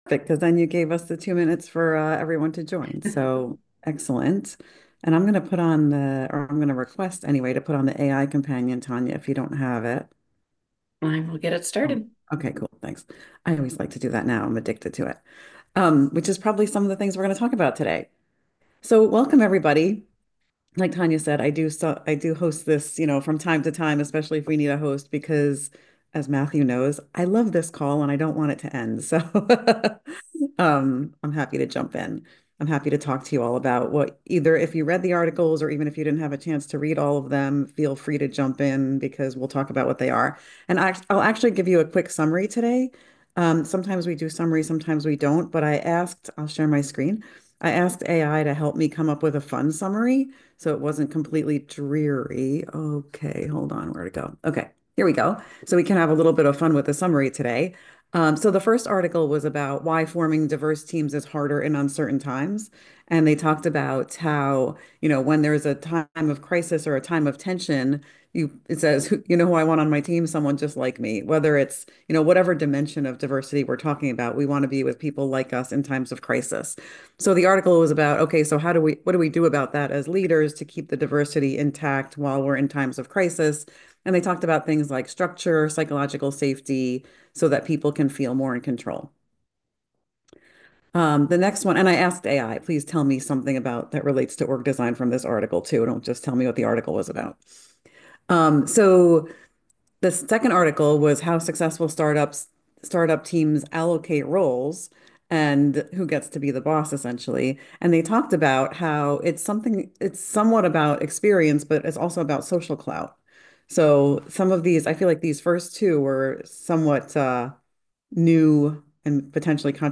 ODF-EODF-Global-OD-Trends-Conversation-May-2025.m4a